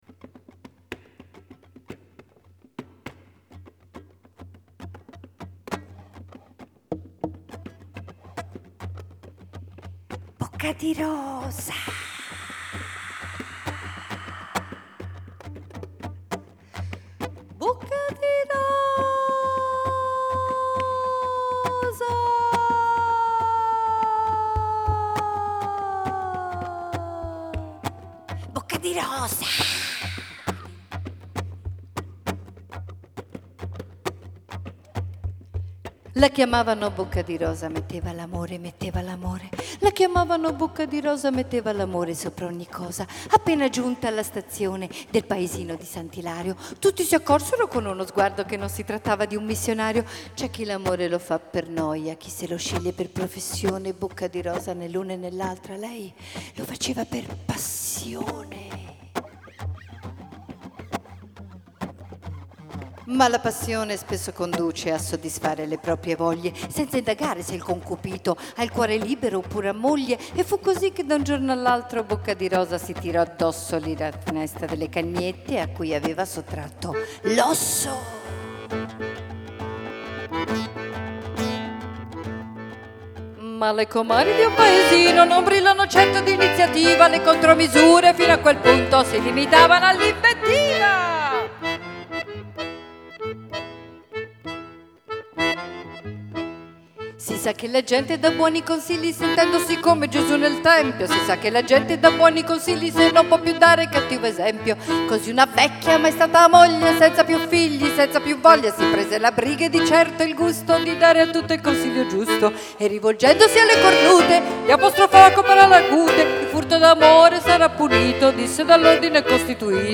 Alcuni brani live registrati in occasione del concerto
chitarra
fisarmonica